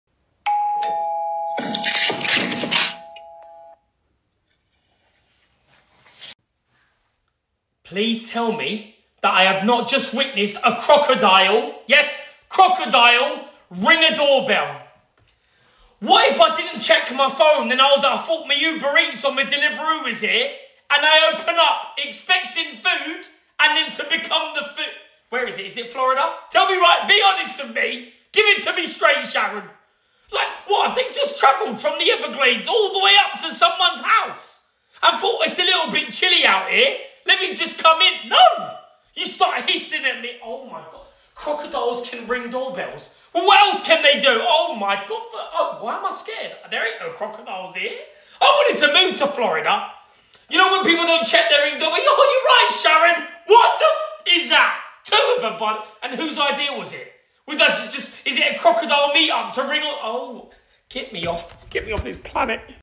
A CROCODILE RINGING A DOORBELL